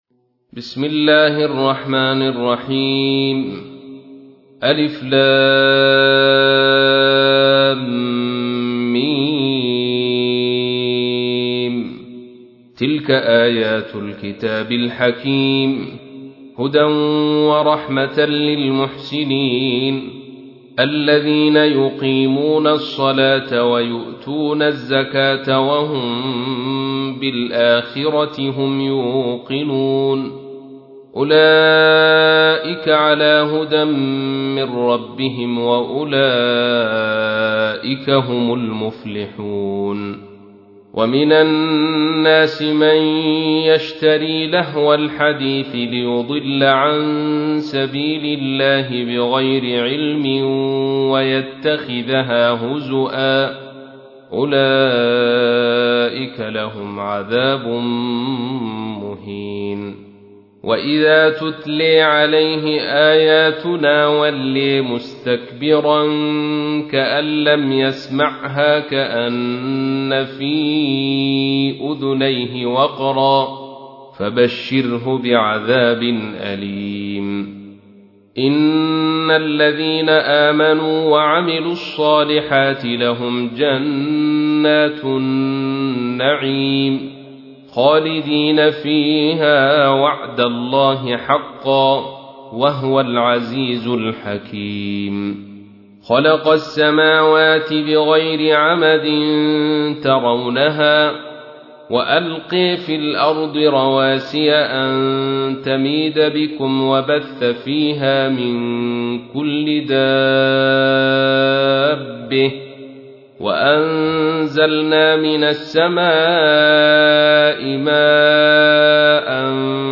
تحميل : 31. سورة لقمان / القارئ عبد الرشيد صوفي / القرآن الكريم / موقع يا حسين